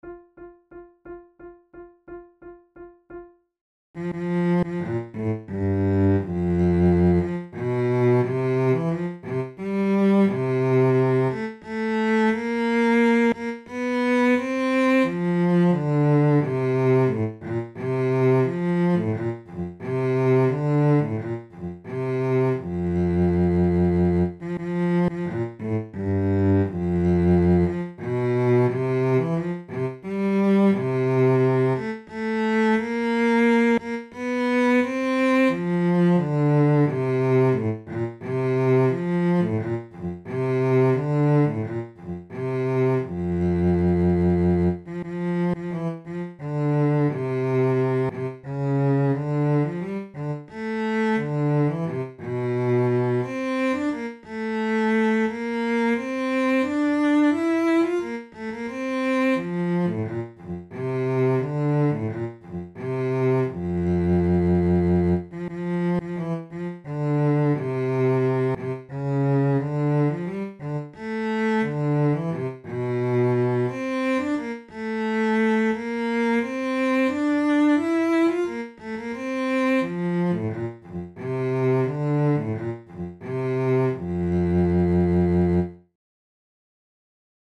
from Trio Sonata in F major, transcribed for two flutes
Corelli was a violinist, and this sonata was originally scored for two violins and continuo; however, it can be played without issues by two flutes.
Categories: Baroque Courantes Sonatas Difficulty: intermediate